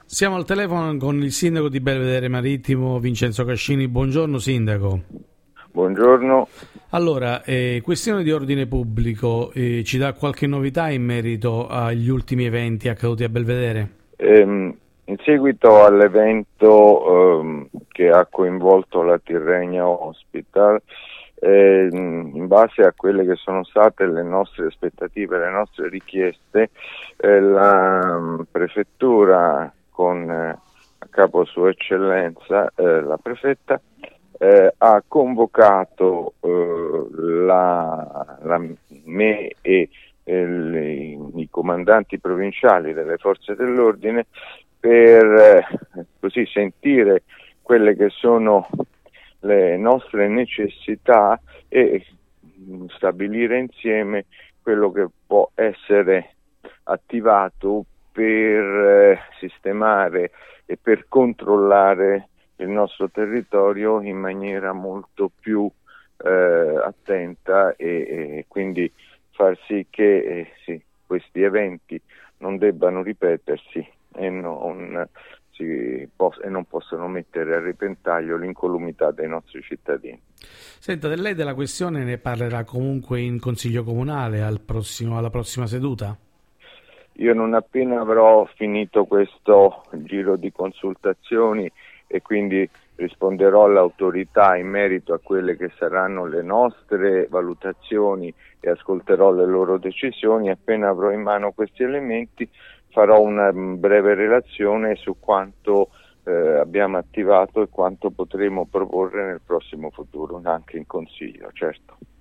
L’intervista al sindaco di Belvedere Marittimo, Vincenzo Cascini